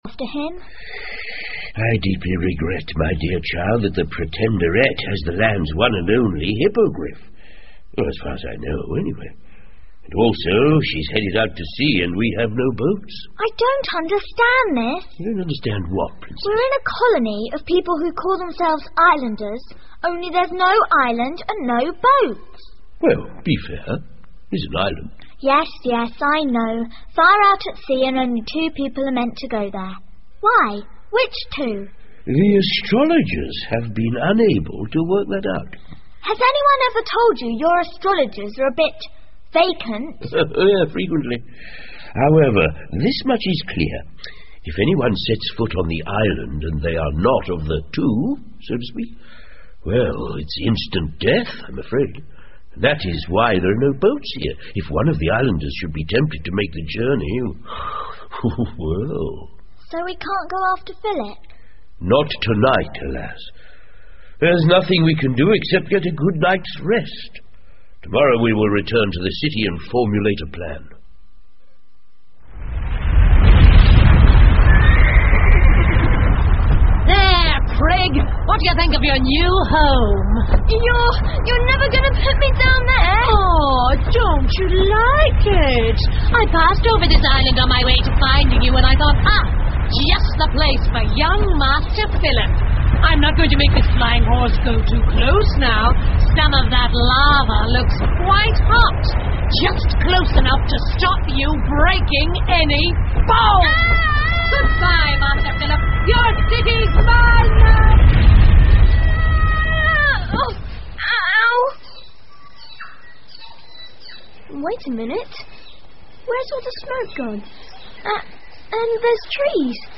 魔法之城 The Magic City by E Nesbit 儿童广播剧 19 听力文件下载—在线英语听力室